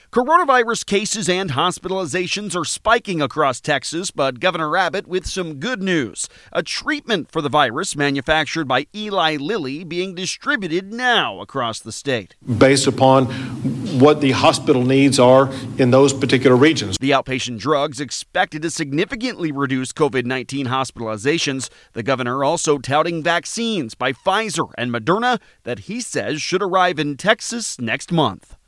WBAP/KLIF News.